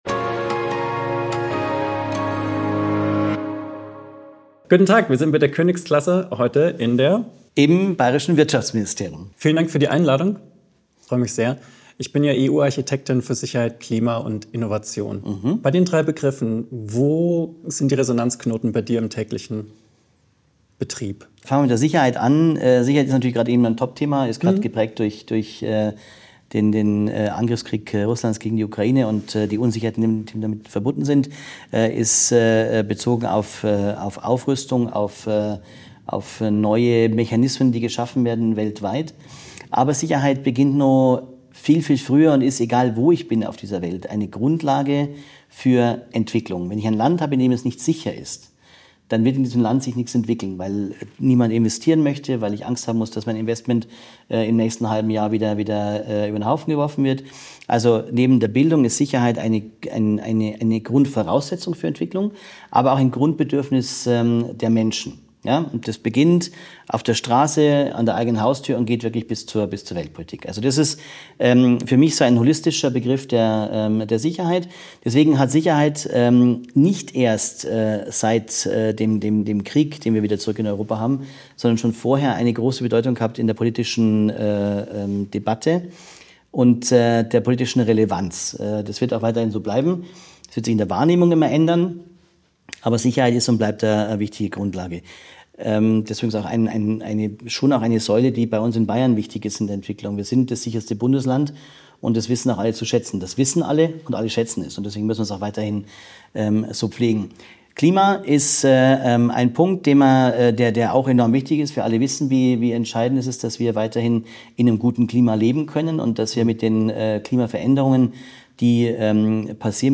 Staatssekretär Tobias Gotthardt im Gespräch